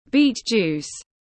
Nước ép củ dền tiếng anh gọi là beet juice, phiên âm tiếng anh đọc là /biːt ˌdʒuːs/
Beet juice /biːt ˌdʒuːs/